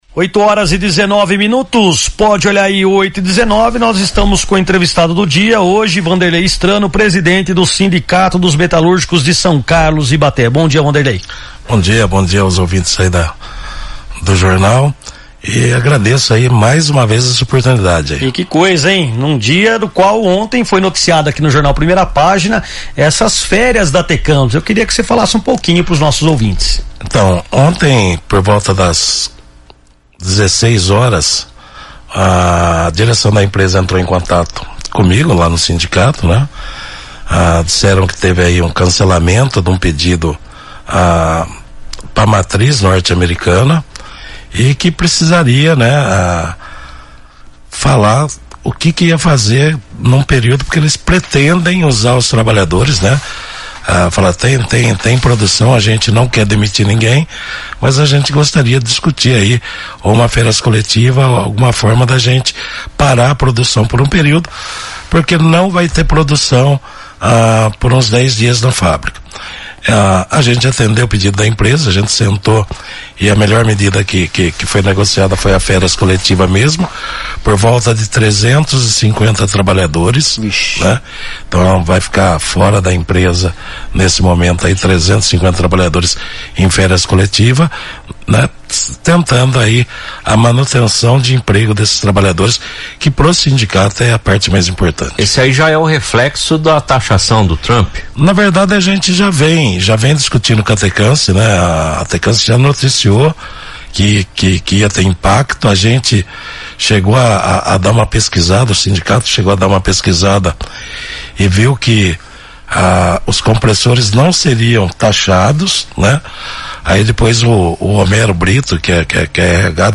A indústria metalúrgica de São Carlos e Ibaté, um dos polos mais importantes do interior paulista, vive dias de apreensão diante dos efeitos das tarifas impostas pelos Estados Unidos a produtos brasileiros e da desaceleração de encomendas. Em entrevista à rádio São Carlos FM